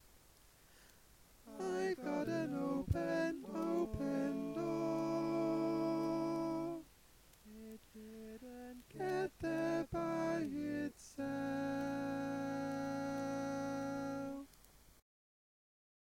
Key written in: A Major
Type: Barbershop